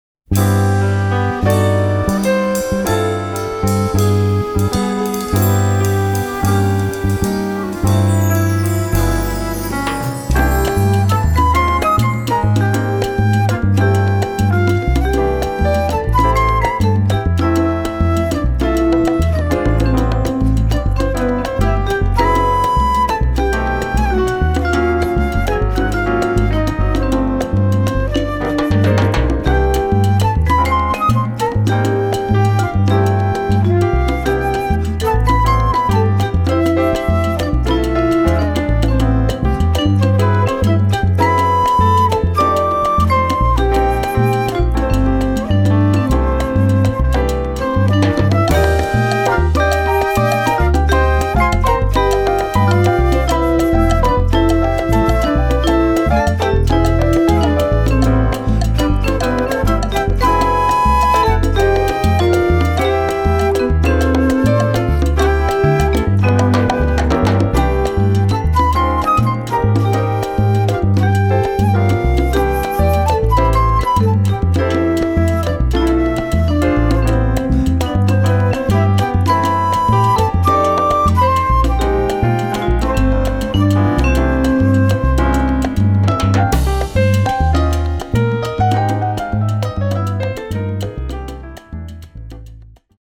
Category: combo
Style: mambo
Solos: open
Instrumentation: vibes, flute 1-2, rhythm section
Instrumentation: (septet) vibes, flute 1-2, rhythm (4)